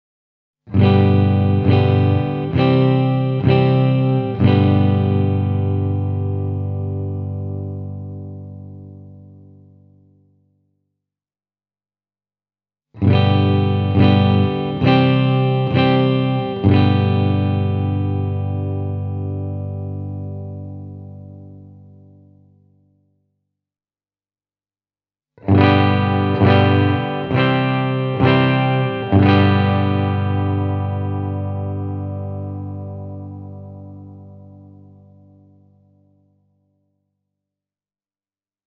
Vahvistimen läpi tämä pieni ero kuitenkin häviää, ja Edwardsin ja referessikitaran väliset pienet soundilliset erot johtuvat varmaan enemmän soittimien eri mikrofoneista kuin niiden perusäänistä.